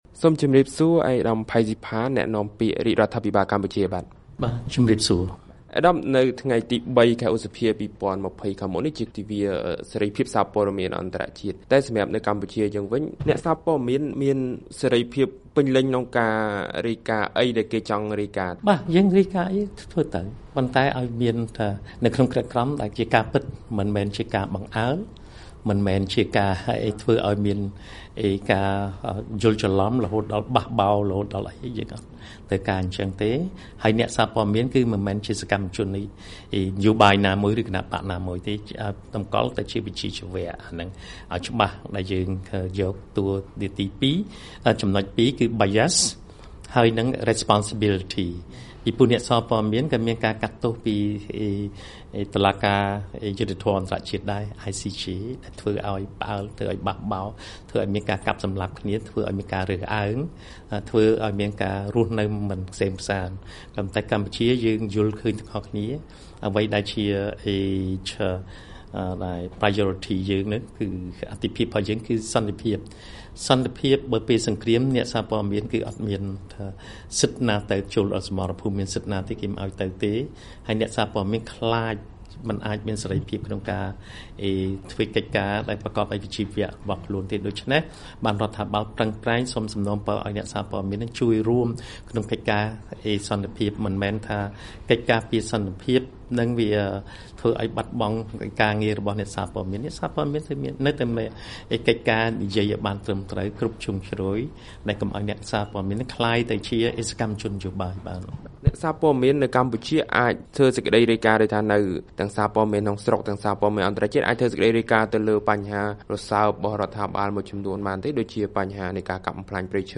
បទសម្ភាសន៍ VOA៖ លោកផៃ ស៊ីផាន ថាពាក្យ«សារព័ត៌មានឯករាជ្យ»ត្រូវគេប្រើសម្រាប់ធ្វើបដិវត្តន៍
ក្នុងបទសម្ភាសន៍មួយជាមួយវិទ្យុសំឡេងសហរដ្ឋអាមេរិក (VOA) លោក ផៃ ស៊ីផាន ប្រធានក្រុមអ្នកនាំពាក្យរដ្ឋាភិបាល បានលើកឡើងថា អ្នកសារព័ត៌មានមិនគួរខ្លាចមិនហ៊ានសរសេរអ្វីដែលខ្លួនចង់រាយការណ៍ឡើយ តែមិនត្រូវធ្វើរំលងច្បាប់របស់ប្រទេស។